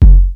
boomp.wav